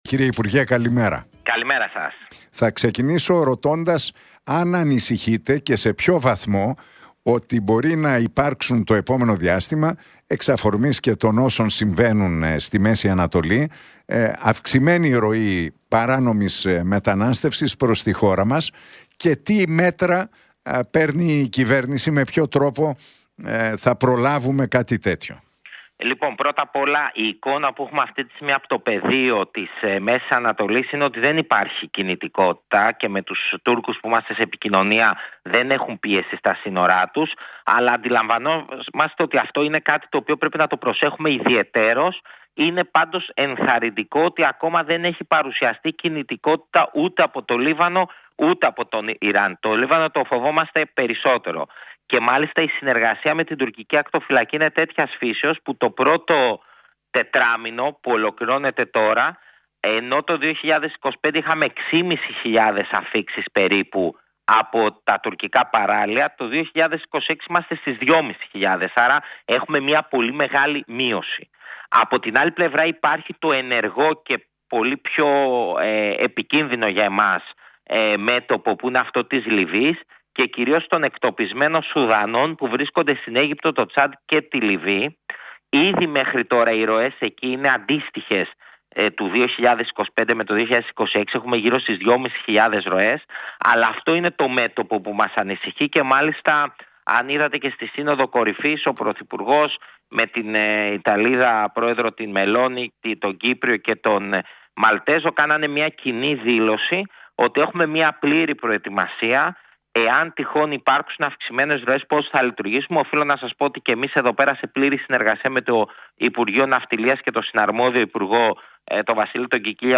Για το μεταναστευτικό, τις αιχμές που άφησε για το πρόσωπό του η Αφροδίτη Λατινοπούλου και την ανάρτηση του Άκη Σκέρτσου, μίλησε ο υπουργός Μετανάστευσης και Ασύλου Θάνος Πλεύρης, στην εκπομπή του Νίκου Χατζηνικολάου στον Realfm 97,8.